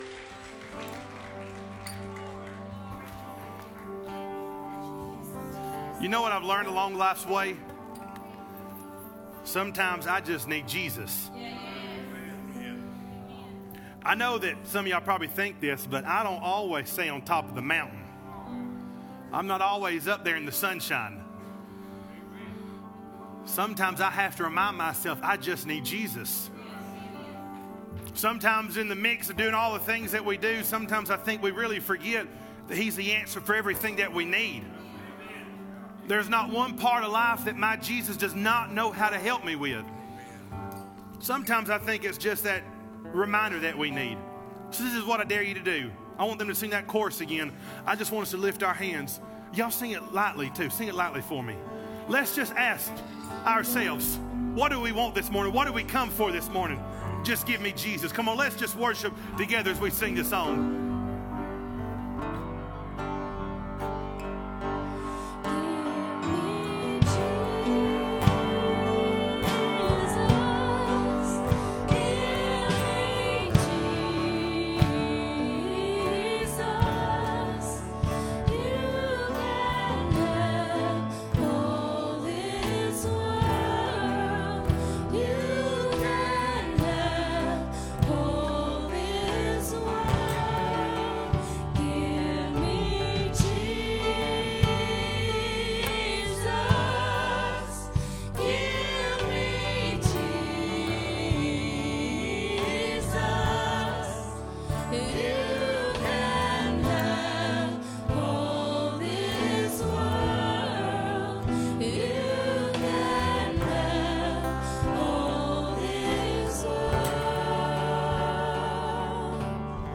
Sermons | Living Light Church of God